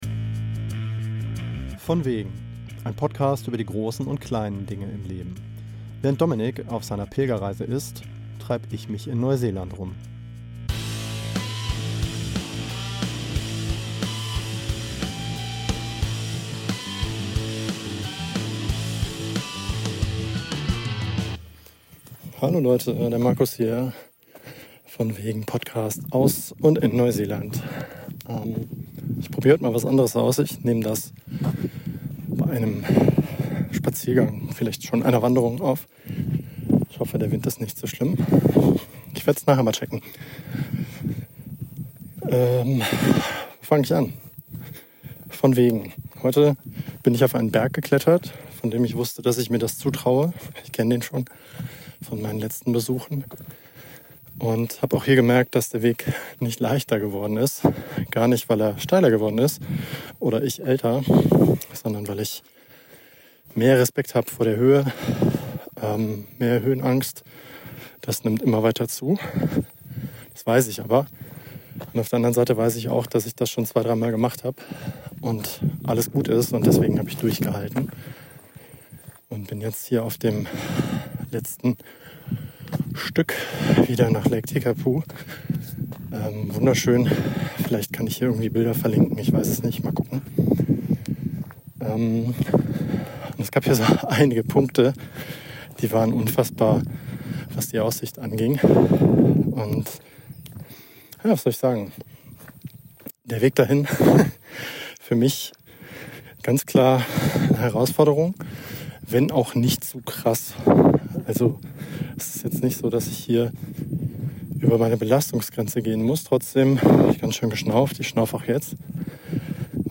Viel Spaß mit der kurzen Episode und bitte entschuldigt den Wind.